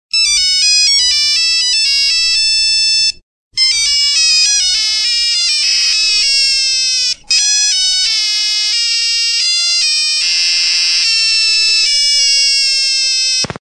Broken Phone Sound Effect Free Download
Broken Phone